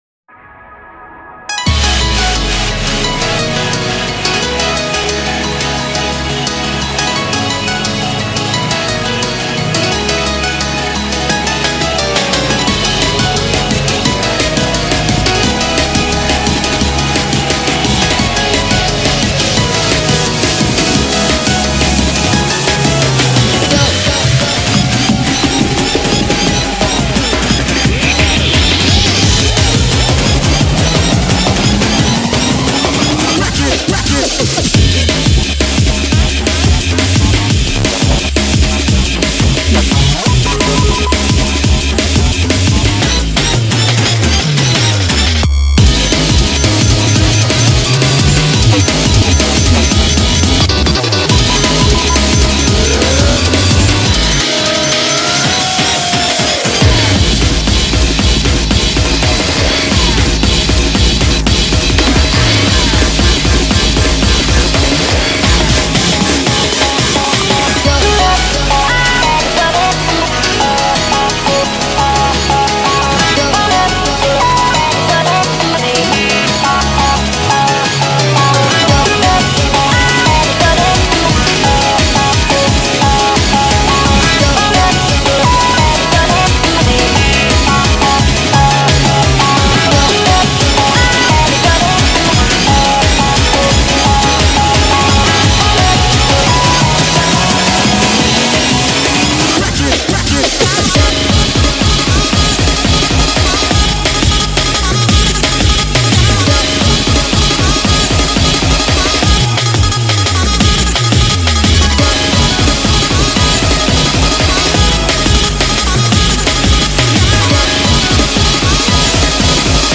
Audio QualityPerfect (Low Quality)